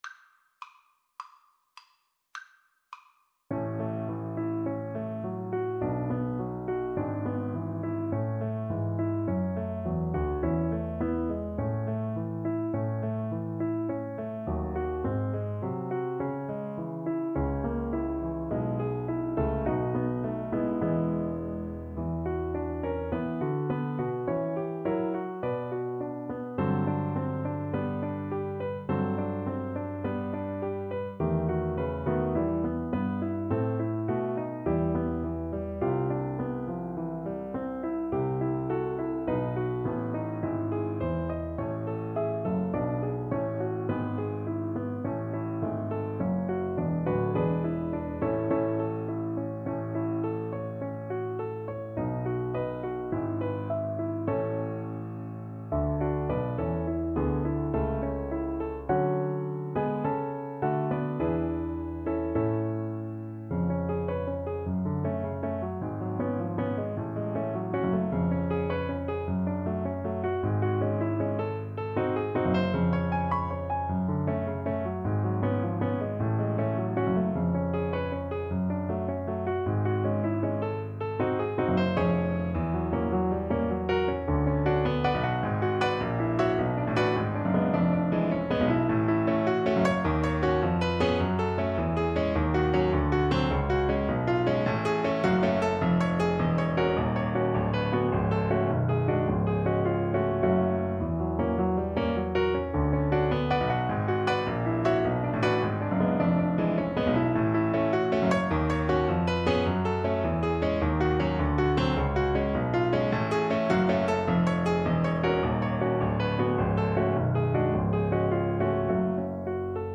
Einfach, innig =104
Classical (View more Classical Viola Music)